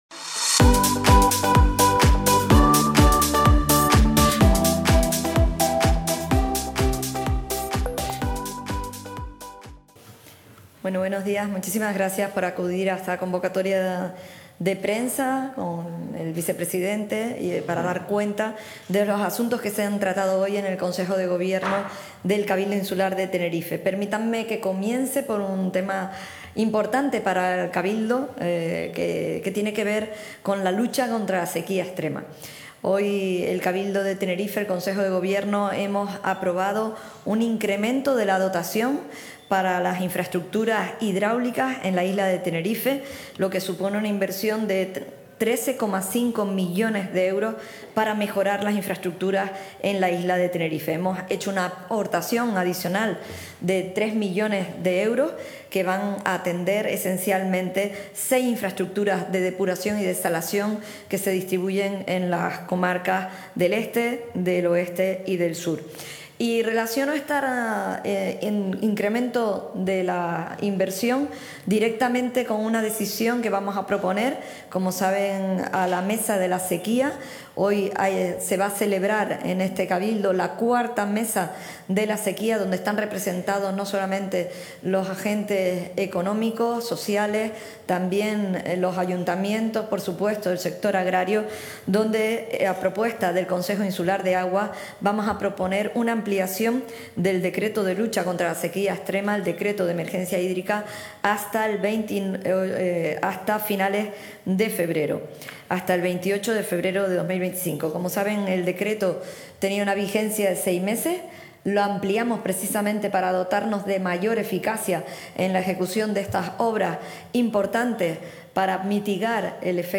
Recientemente emitido: Rueda de Prensa de presentación de los acuerdos del Consejo de Gobierno.